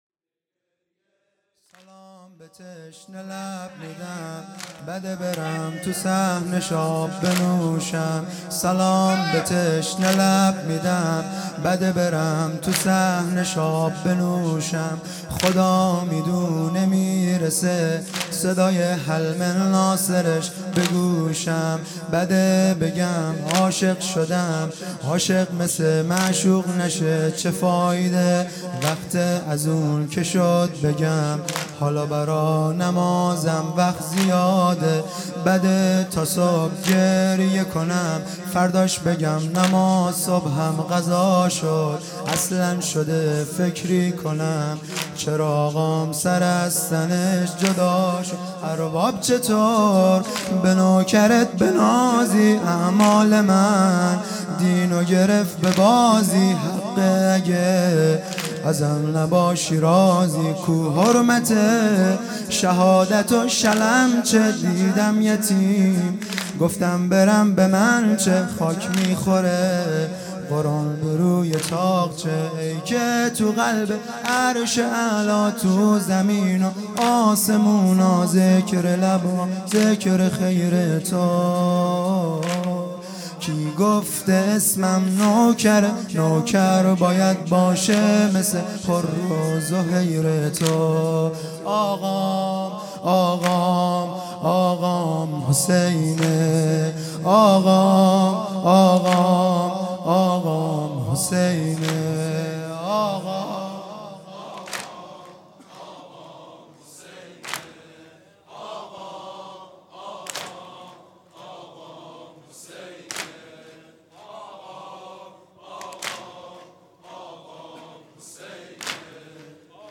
شب چهارم محرم